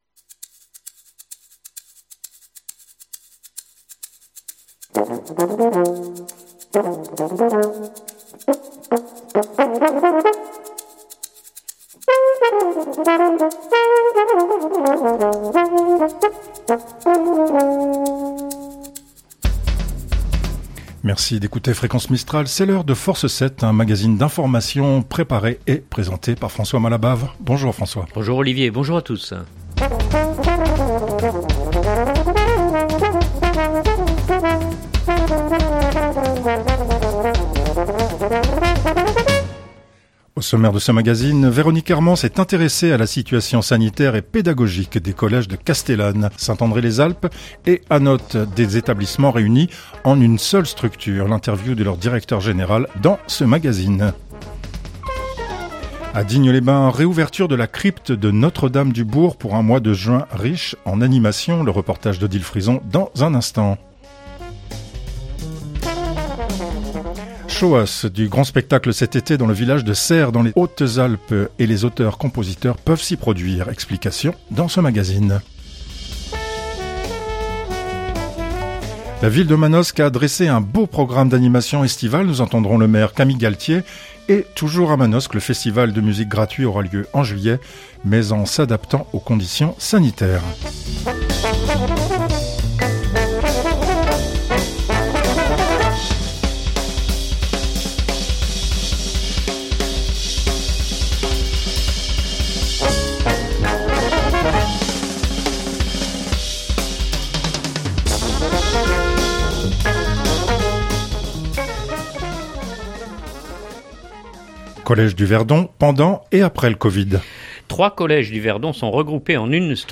La ville de Manosque a dressé un beau programme d’animations estivales. Nous entendrons le maire Camille Galtier.